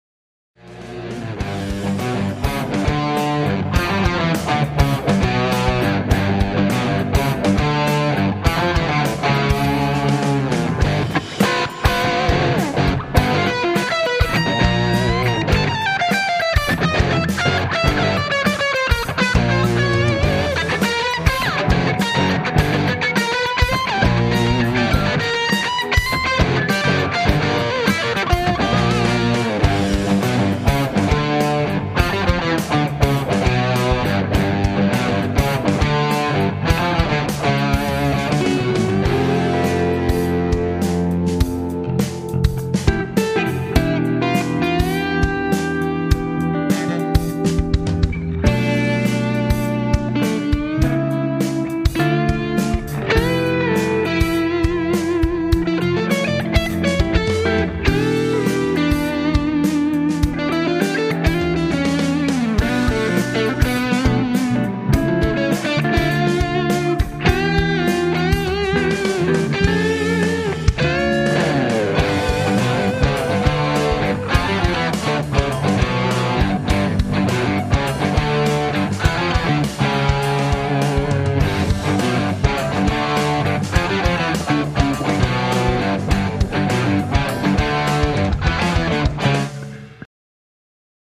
Instrumentalstücke bei denen ich meinen Ideen auf der Gitarre freien Lauf lassen kann.